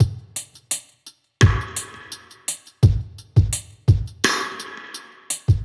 Index of /musicradar/dub-drums-samples/85bpm
Db_DrumKitC_Wet_85-01.wav